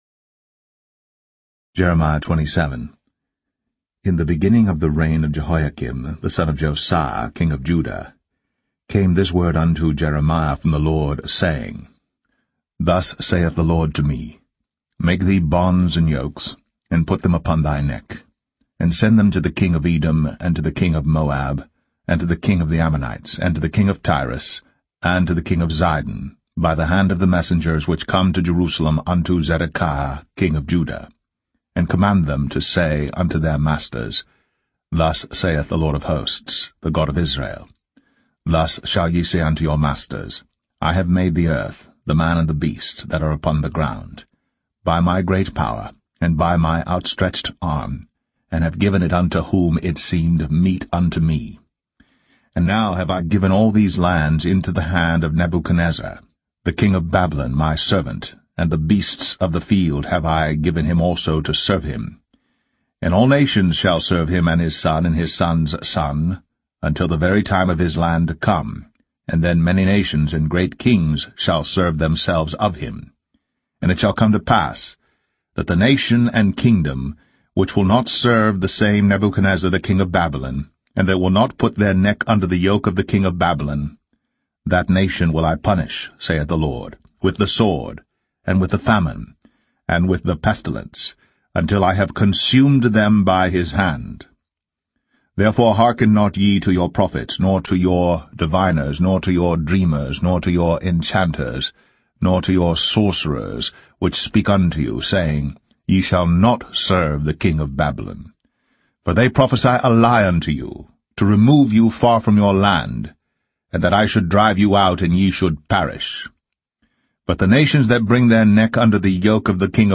[Apr 19, 2023] CuttingEdge: Jeremiah 27 Reading